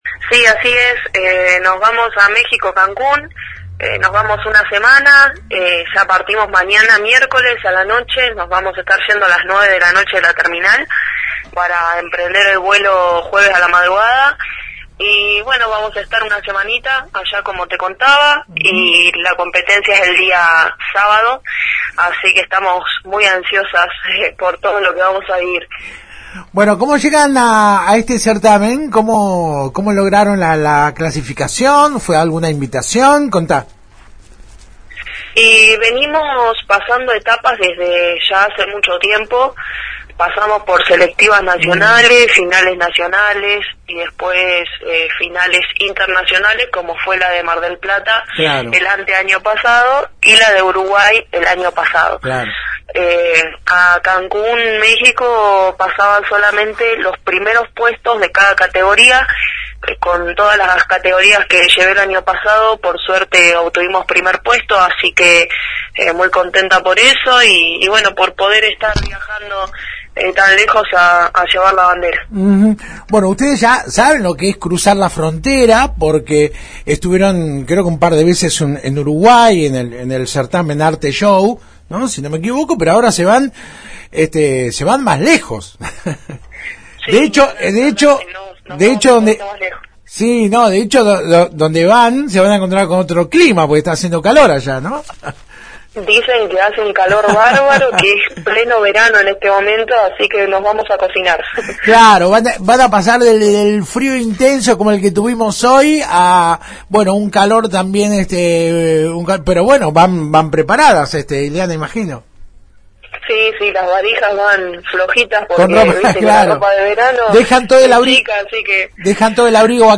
En comunicación telefónica con la 91.5, la joven instructora señaló que «ya estamos en los momentos previos al viaje.